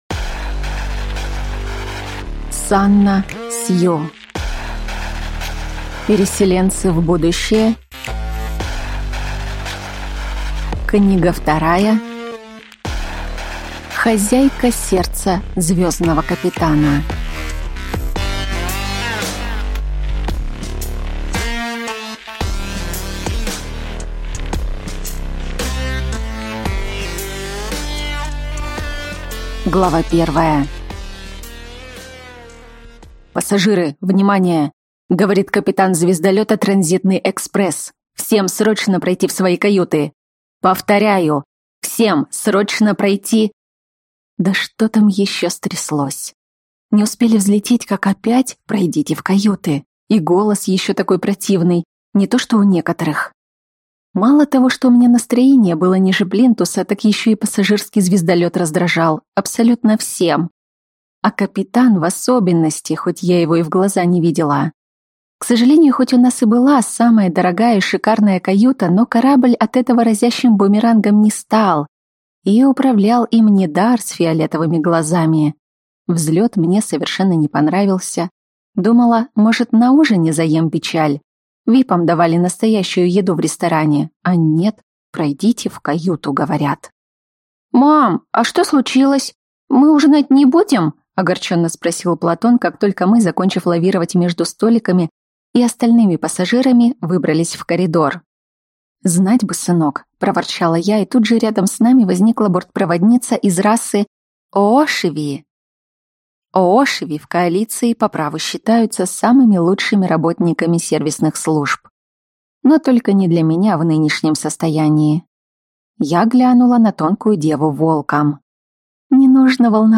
Аудиокнига Переселенцы в будущее. Книга 2. Хозяйка сердца звёздного капитана | Библиотека аудиокниг